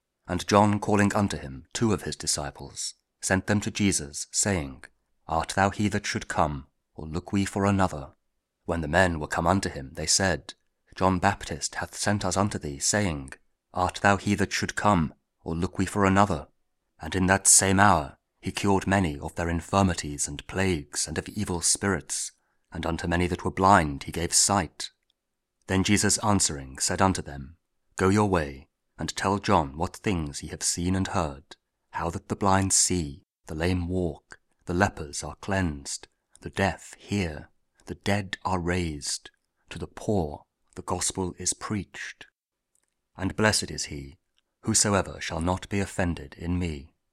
Audio Daily Bible